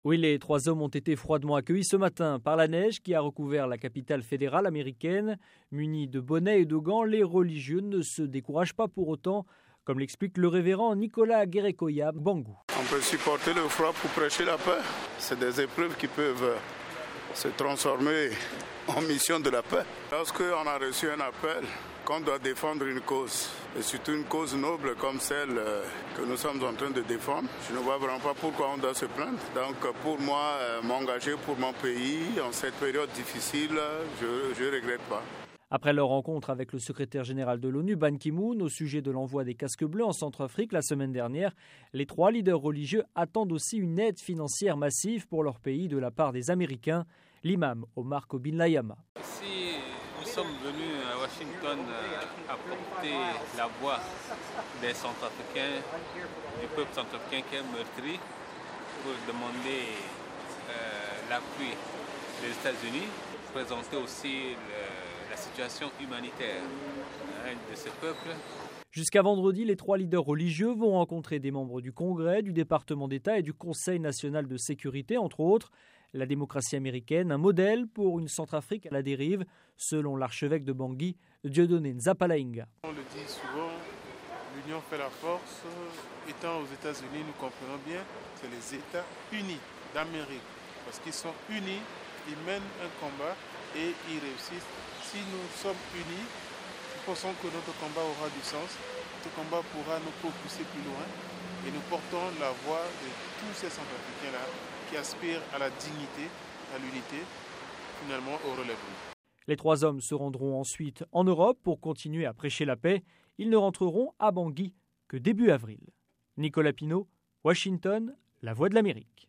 Reportage à Washington avec les leaders religieux centrafricains